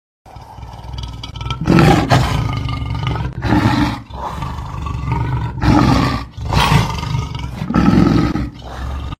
rugido_leon.mp3